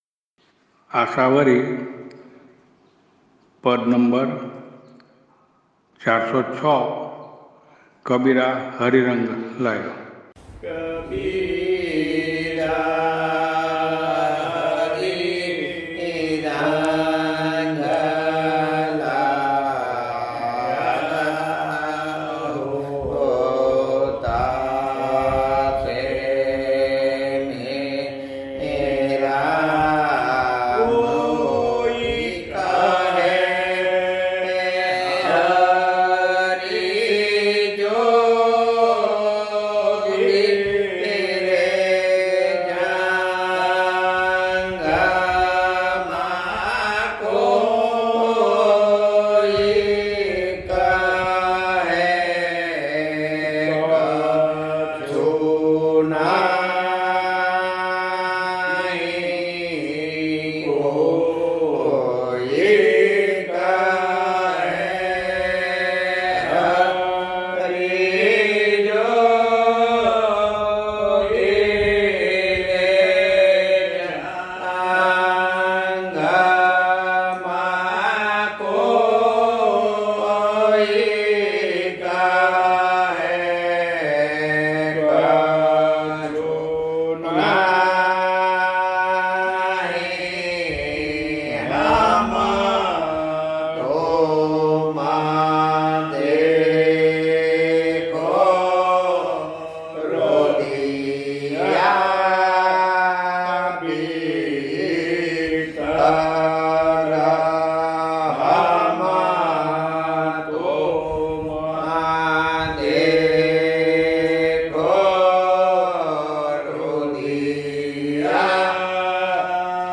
નાદબ્રહ્મ પદ - ૪૦૬, રાગ - મોટી આશાવરીNādbrahma pada 406, rāga - moti āshāvari